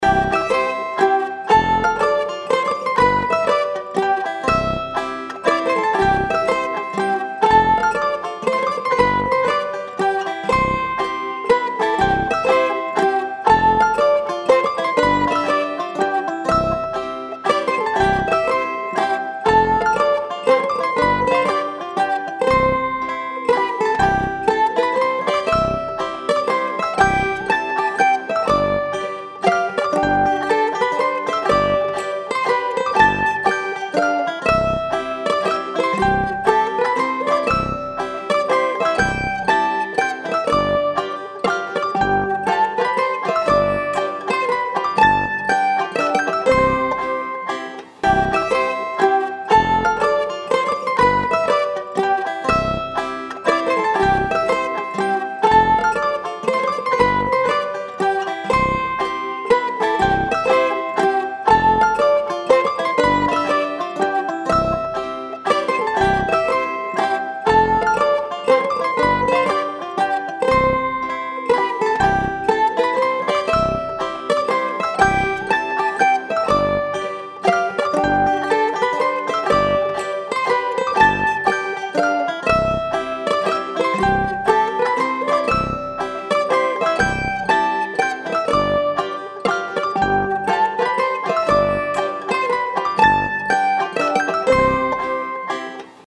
From Australian Traditional Music Tunes
R:Varsovienne
M:3/4
K:C